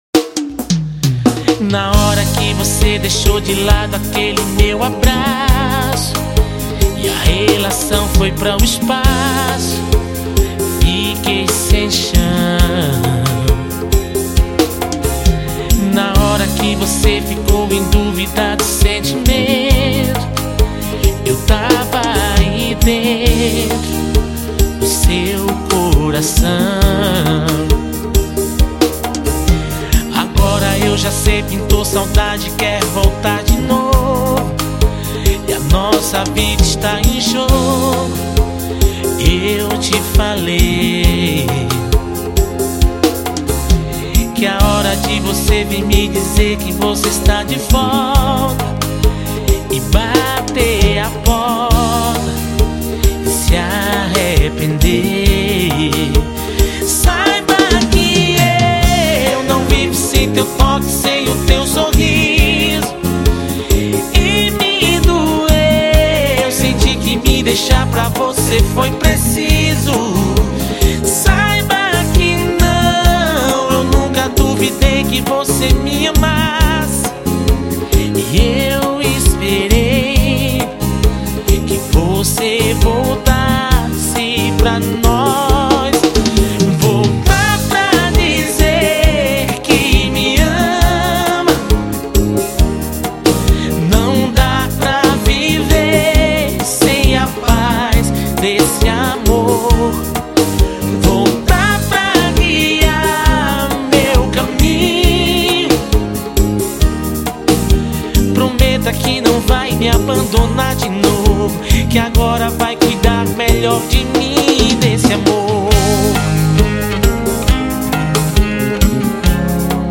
AO VIVO.